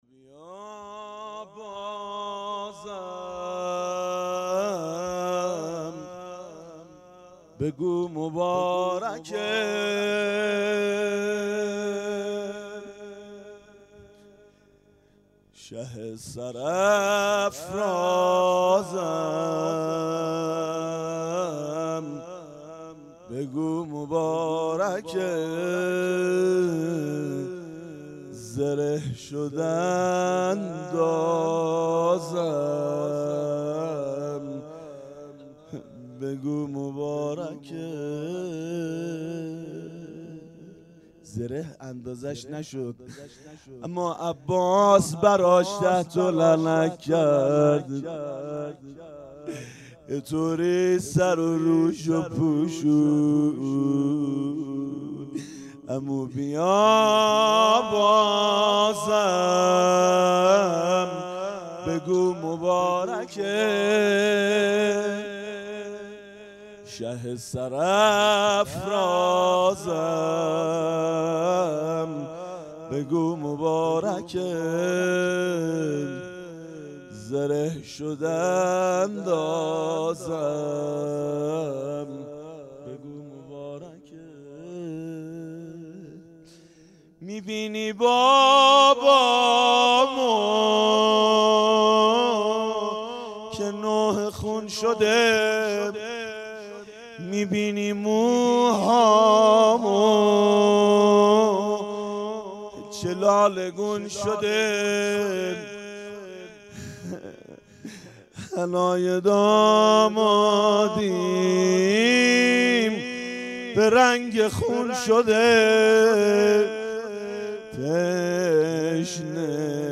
حسینیه بنی فاطمه(س)بیت الشهدا
0 0 روضه شب ششم محرم 97